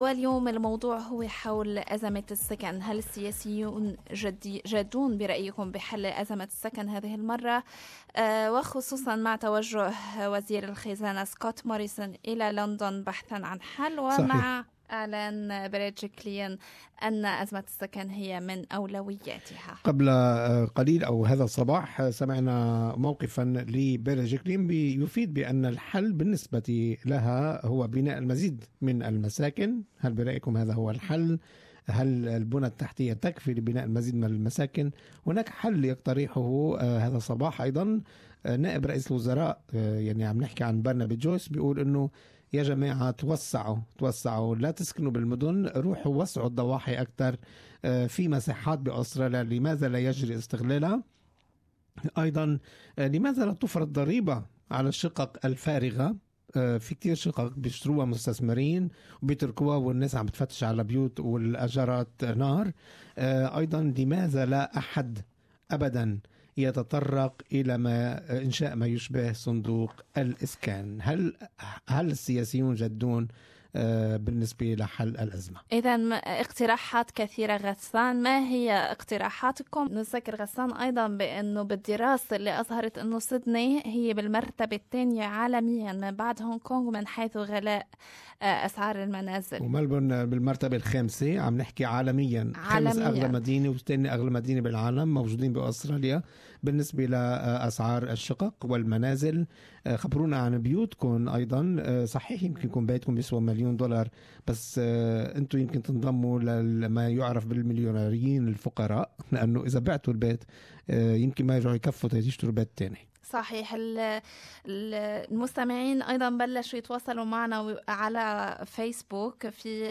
Good Morning Australia listeners share their opinions.